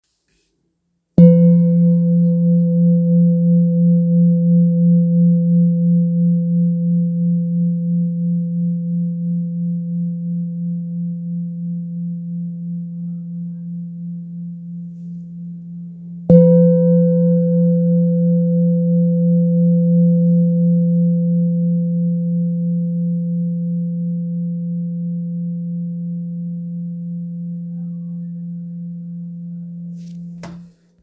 Jambati Singing Bowl-30633
Jambati Singing Bowl, Buddhist Hand Beaten, Antique Finishing, Select Accessories
Material Seven Bronze Metal
Jamabati bowl is a hand-beaten bowl.
It can discharge an exceptionally low dependable tone.